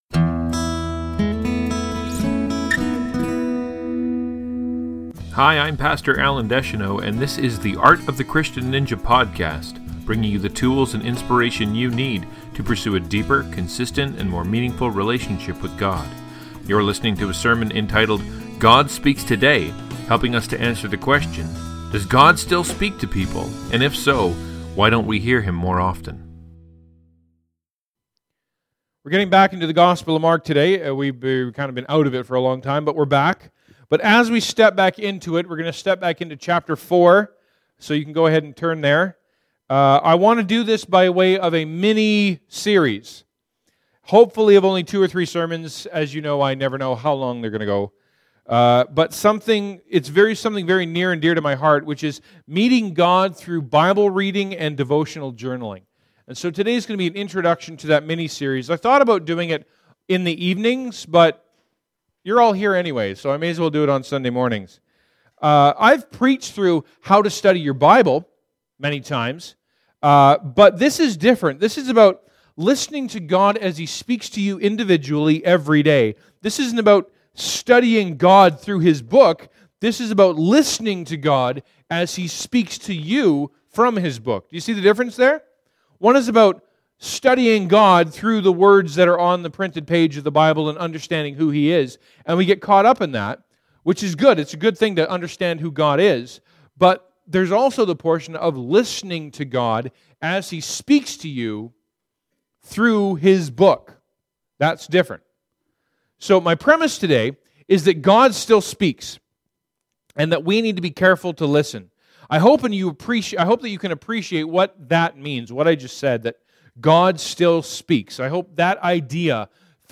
We’re getting back into the Gospel of Mark today, but as we step back into it – into Chapter 4 – I want to do it by way of a mini-series, of hopefully only two or three sermons, about something that is very near and dear to my heart – meeting God through bible-reading and devotional journalling.